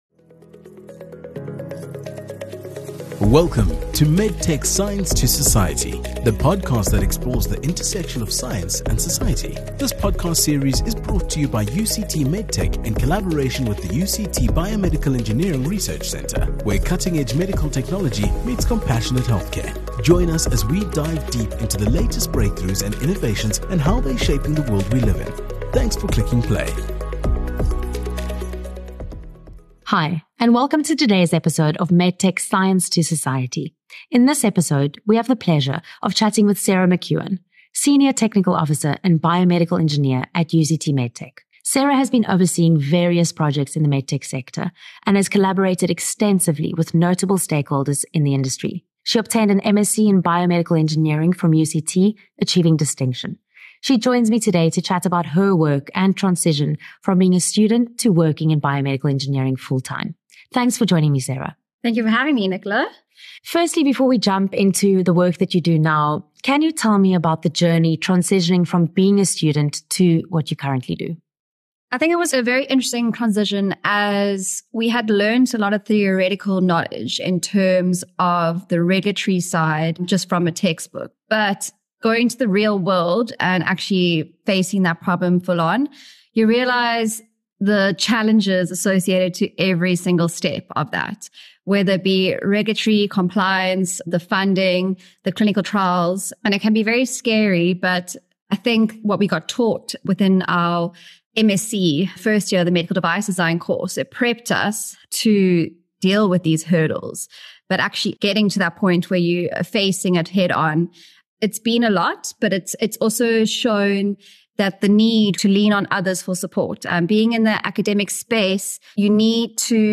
Join us in this enriching conversation that bridges the gap between academic knowledge and real-world application in the field of biomedical engineering.